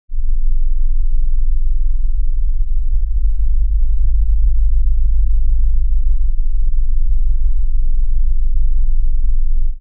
Spaceship Ambience Engine rumbles, control room hums "Deep spaceship engine hum with faint control panel beeps"